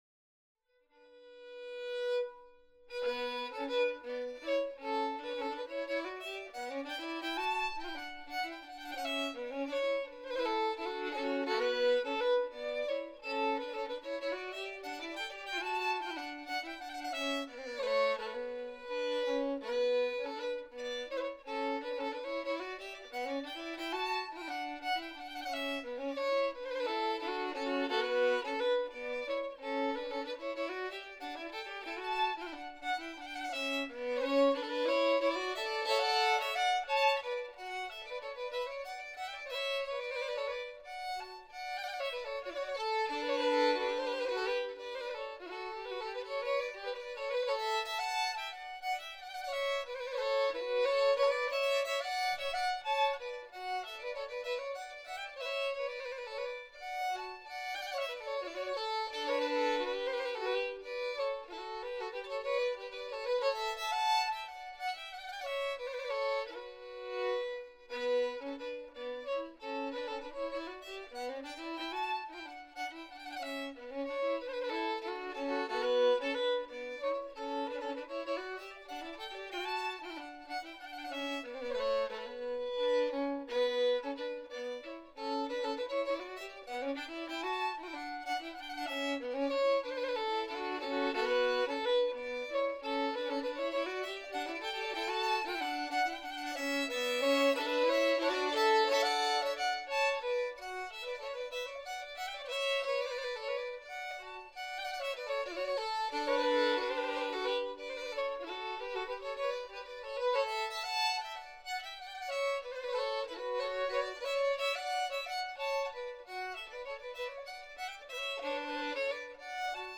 Slängpolska
Smålandstratidion stämma och melodi 2018 (hörs mest melodi).mp3 2.89 MB
Smålandstratidion-stämma-och-melodi-2018-hörs-mest-melodi.mp3